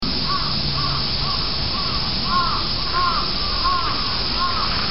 Aprovechando que aun era de día, nos acercamos a Shinjuku (???), vimos desde fuera el Ayuntamiento de Tokio y acto seguido nos acercamos al Central Park Shinjuku para descansar, pero había mas ruido que en la propia calle, los escarabajos de Japón parecen monstruos a parte de el sonido de los cuerbos.
parque_ayuntamiento.mp3